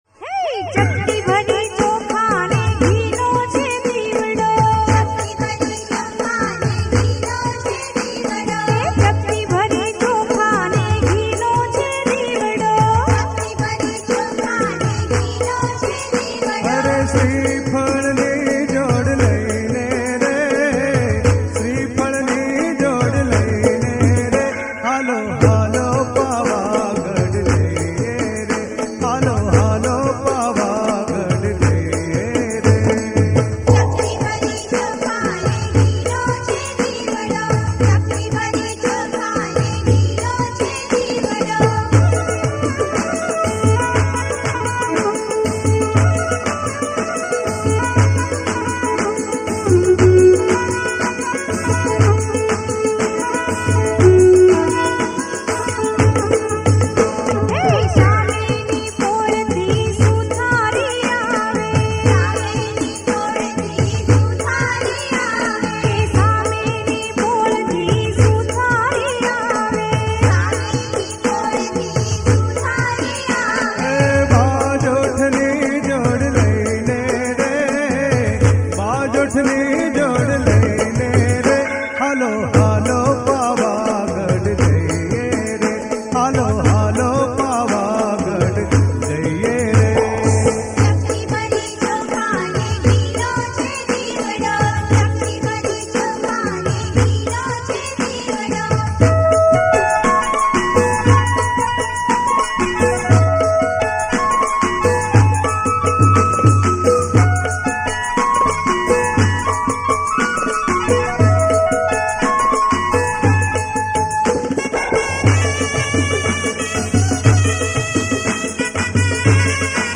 ગીત સંગીત ગરબા - Garba
પ્રાચિન ગરબો || Traditional Hit Navratri Garba Song.